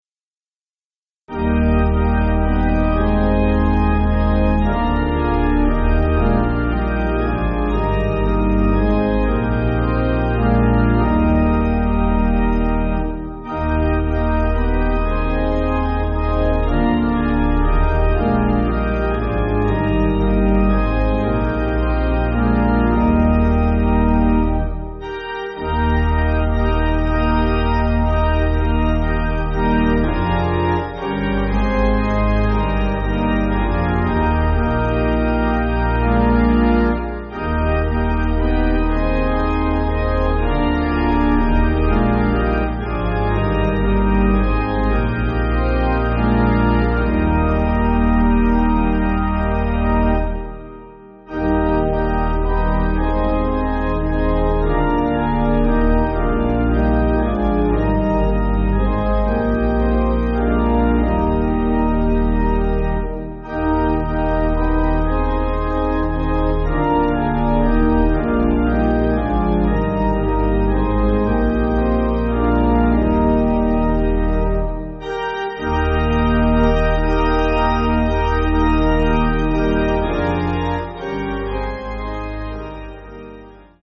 (CM)   5/Eb